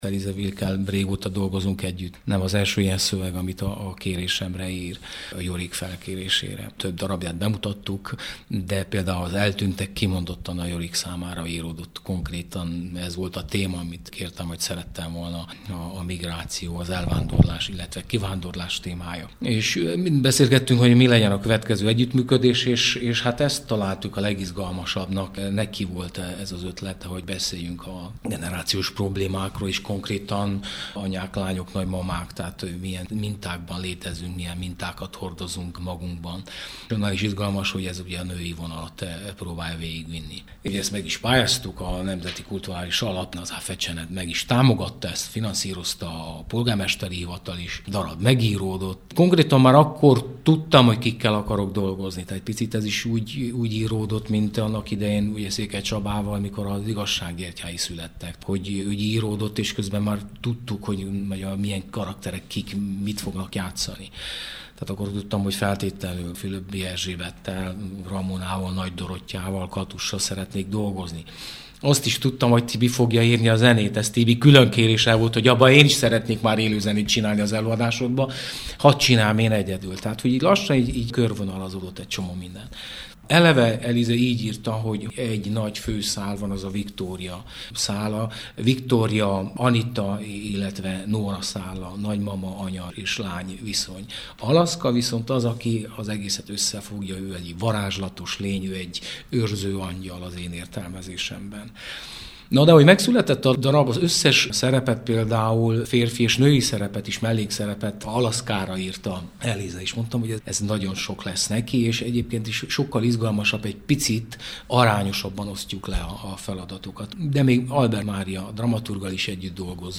az alábbiakban vele beszélget